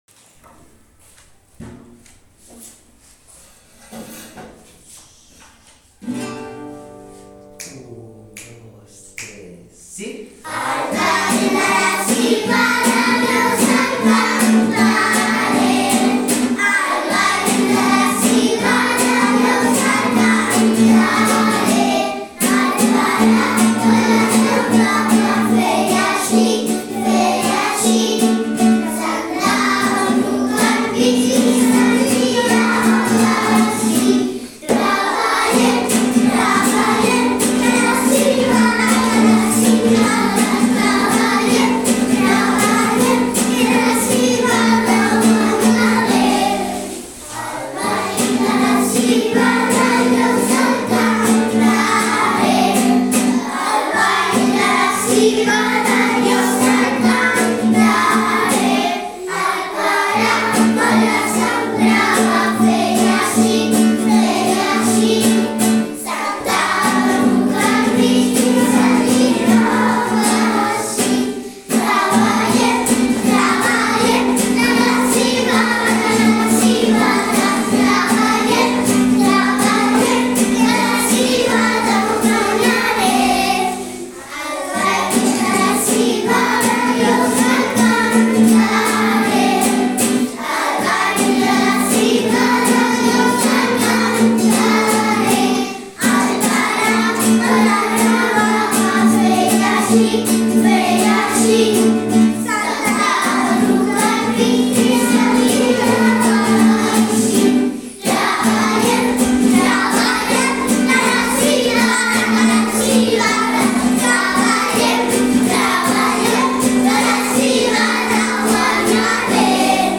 Avui us presentem la cançó que hem treballat amb els alumnes de primer. És una cançó tradicional catalana, que també ens ha ensenyat quins són les diferents etapes per preparar, plantar, etc., els cereals.